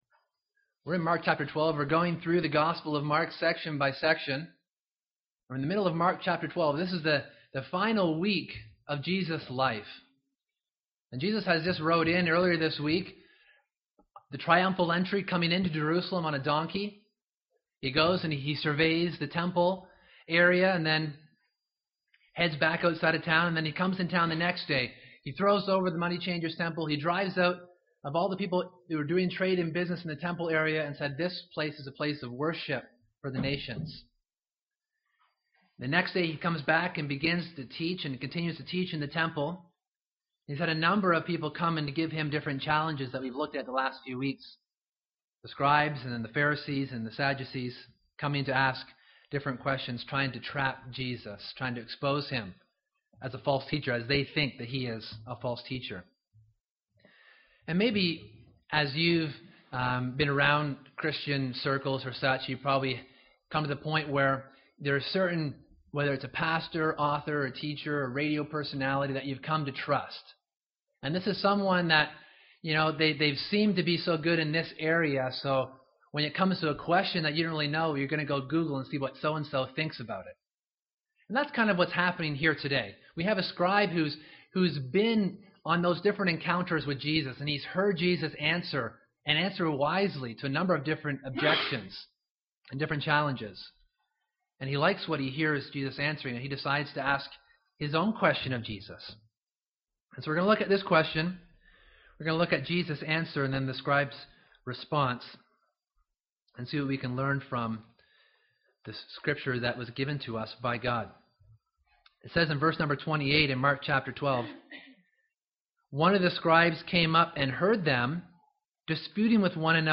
2014 ( Sunday AM ) Bible Text